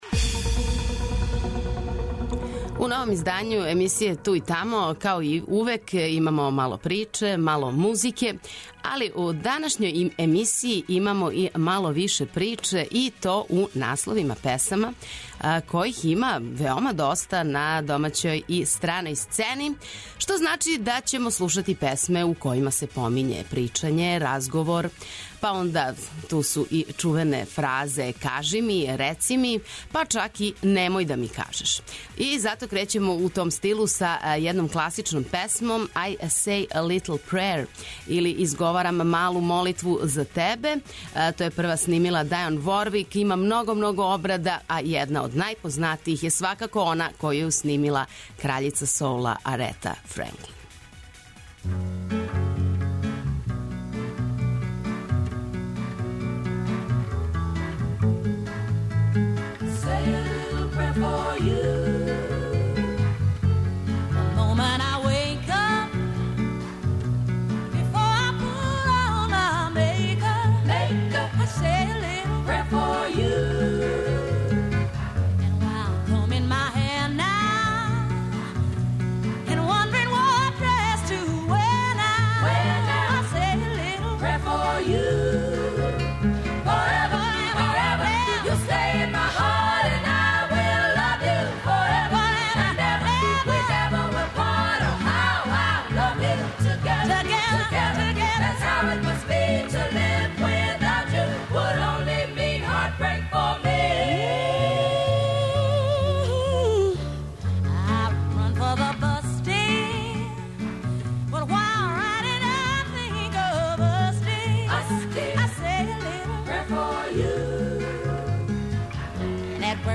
Где престаје говор почиње музика - рекао је музички критичар Хофман, aли у новој емисији Ту и тамо на Двестадвојци биће места и за једно и за друго. То значи да ћемо слушати песме које у насловима доносе разговор, причање, и остале форме типа 'реци ми','кажи' или чак 'не говори'.